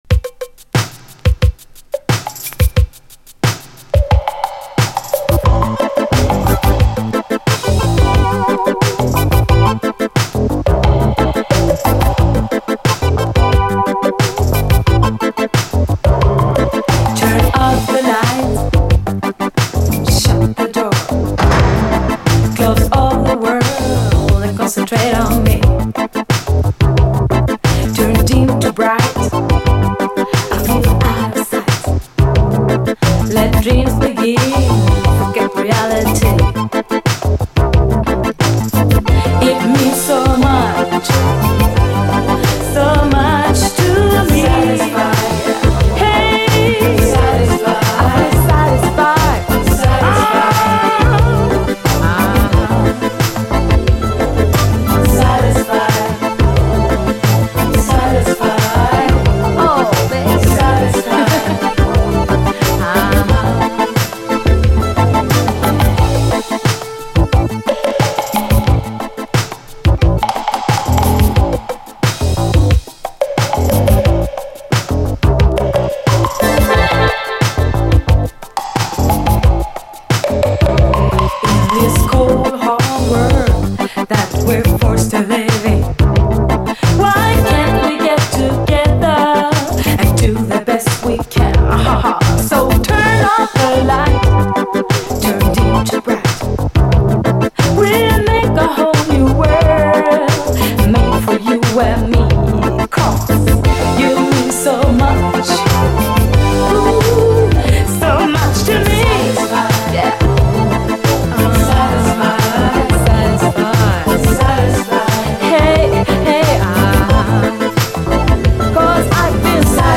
DISCO, REGGAE, 7INCH
超カッコいいスウェーデン産メロウ・イタロ・ディスコ〜異端シンセ・ディスコ・レゲエ！